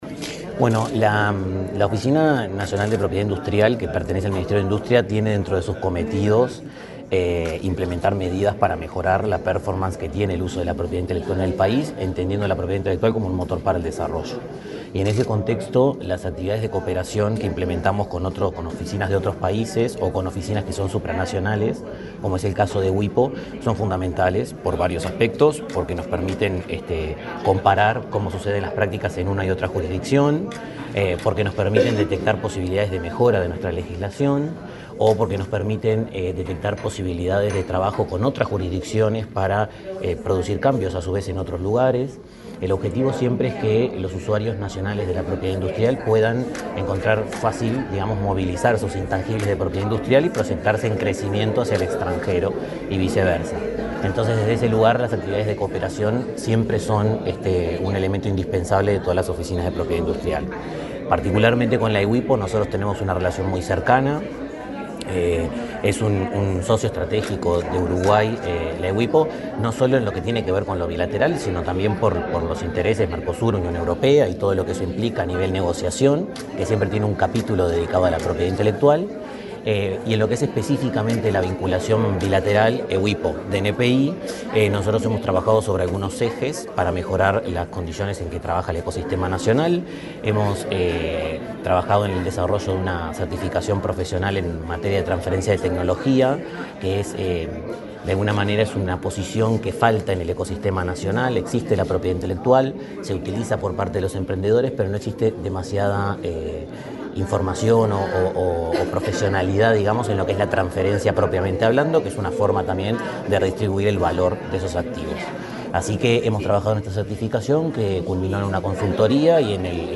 Declaraciones del director de Propiedad Industrial del MIEM, Santiago Martínez
Declaraciones del director de Propiedad Industrial del MIEM, Santiago Martínez 19/02/2025 Compartir Facebook X Copiar enlace WhatsApp LinkedIn Este miércoles 19 en Montevideo, el director de Propiedad Industrial del Ministerio de Industria, Energía y Minería (MIEM), Santiago Martínez, dialogó con la prensa, antes de participar en un desayuno de trabajo acerca de la cooperación entre Uruguay y la Unión Europea en esa materia.